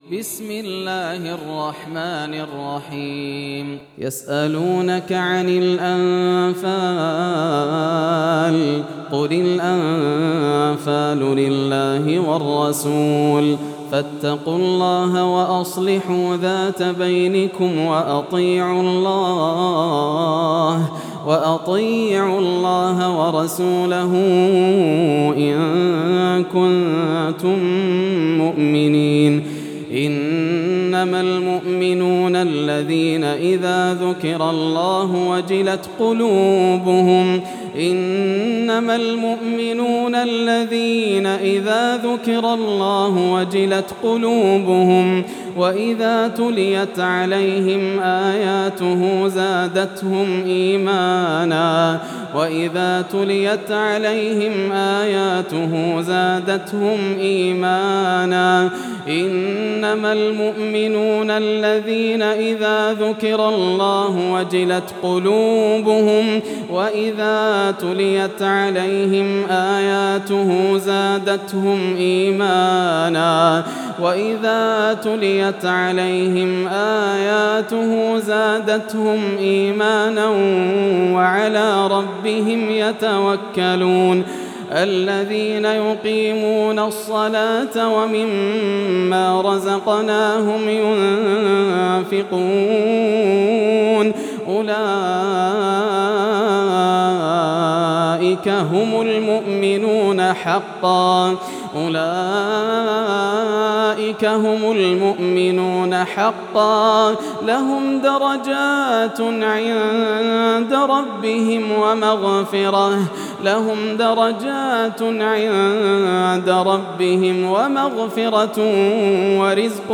سورة الأنفال > السور المكتملة > رمضان 1433 هـ > التراويح - تلاوات ياسر الدوسري